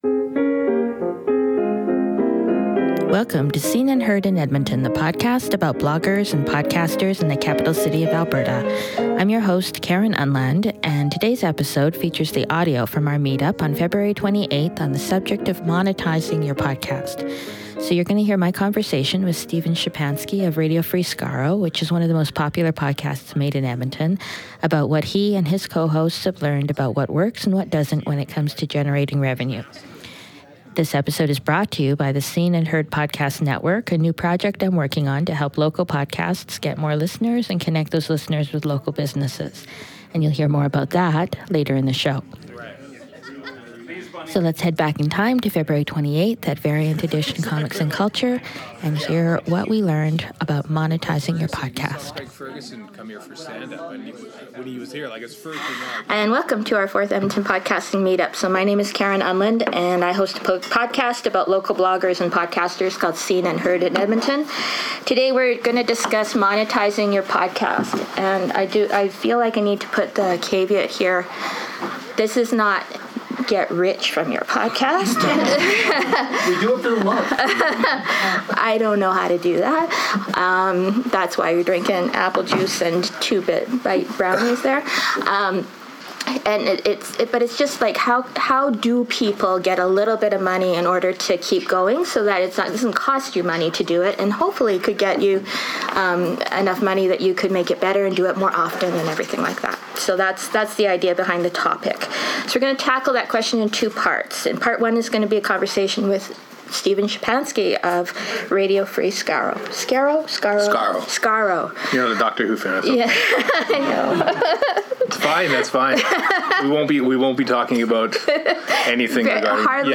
This week’s podcast features the audio from our meetup on monetizing your podcast.